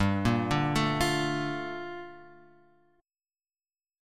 Gm9 chord {3 1 0 2 x 1} chord